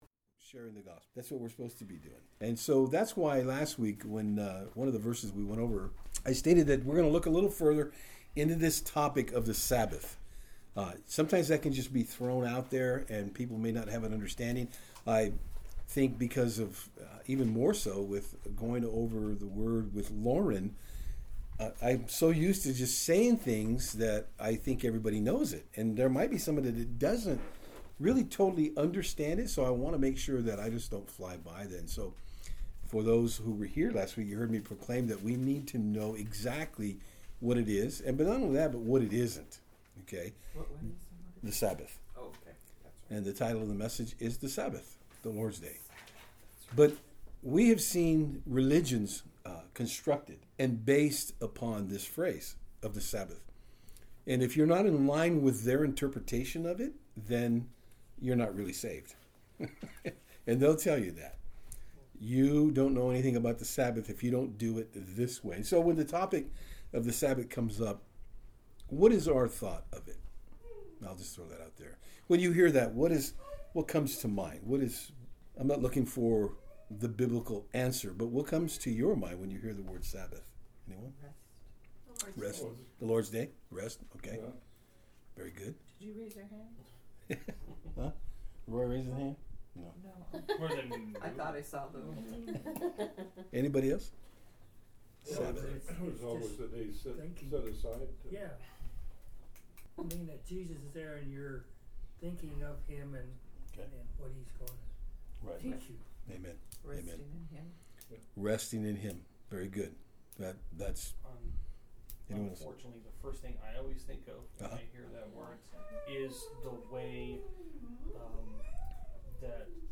Various Service Type: Saturdays on Fort Hill Bible Text